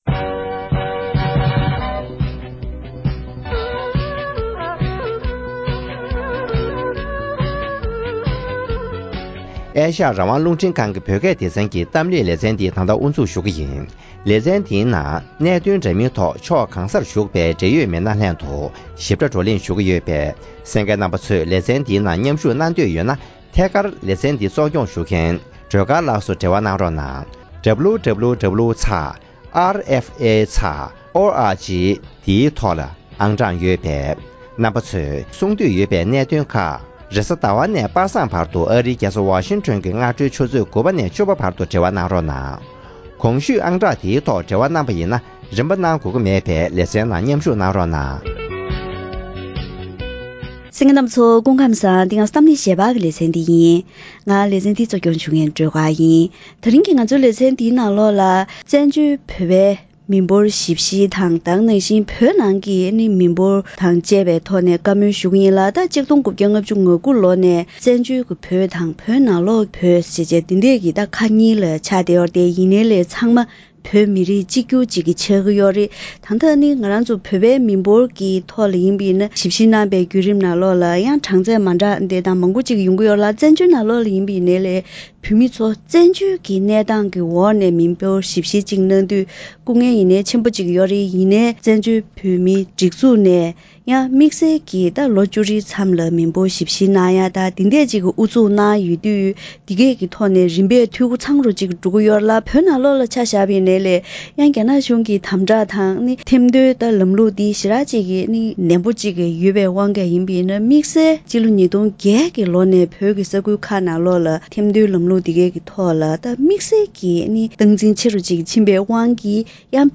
༄༅། །ཐེངས་འདིའི་གཏམ་གླེང་ལེ་ཚན་ནང་།